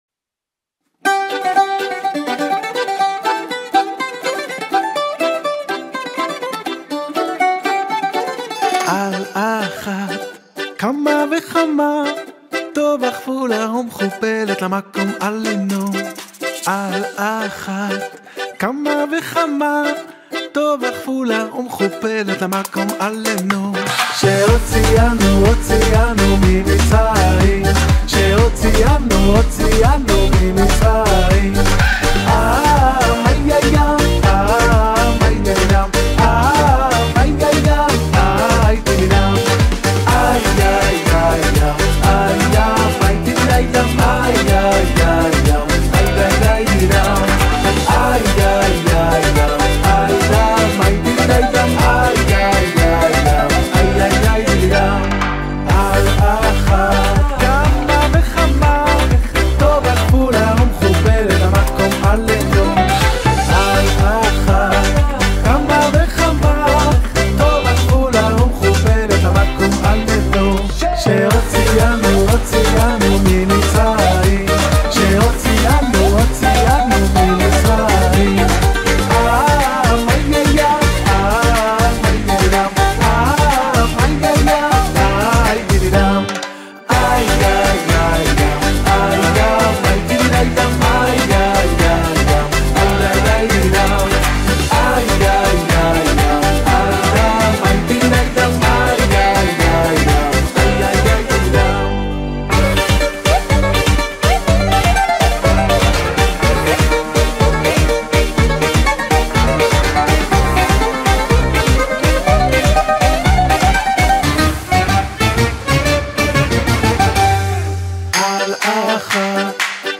הזמר החסידי
גיטרות
בס